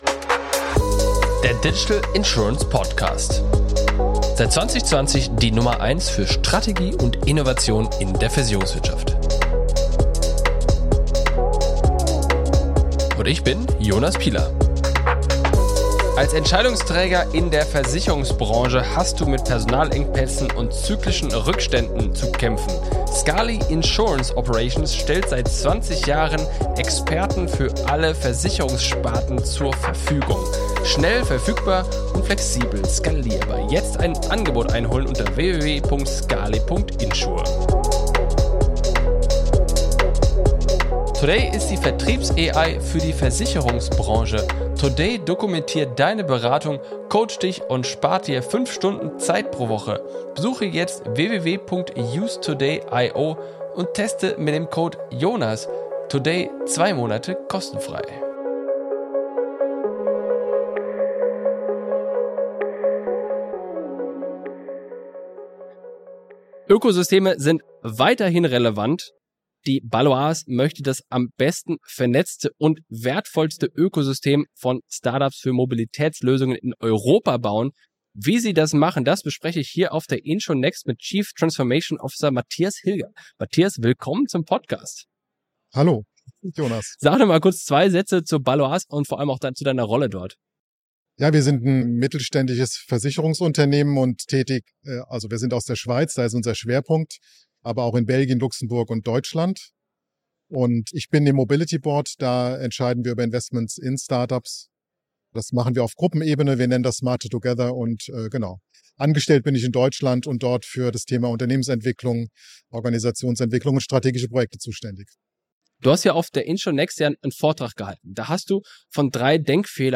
Die Baloise möchte das am besten vernetzte Mobility-Ökosystem von Start-ups aufbauen. Auf der InsurNXT habe ich mich